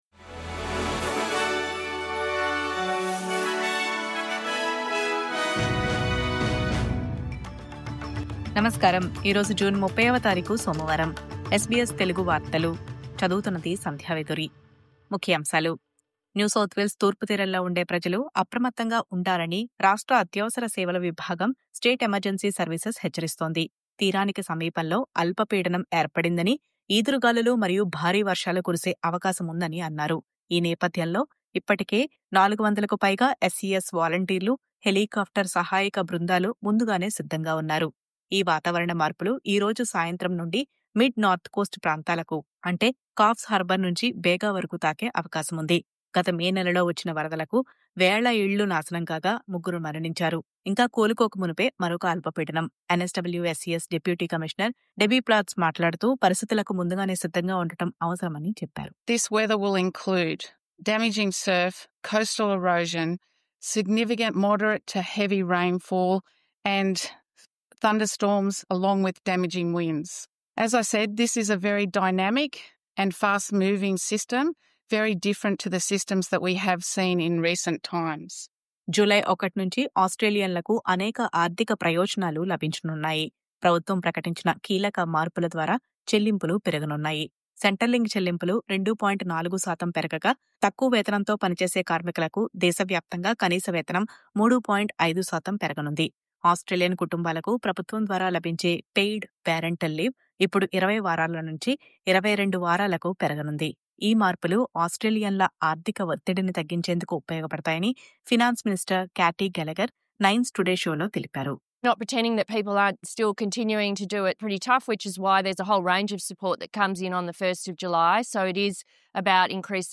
News Update: హౌసింగ్ రంగంలో ట్రేడీలను ఆకర్షించేందుకు – ప్రభుత్వం అందిస్తున్న $10,000 మద్దతు..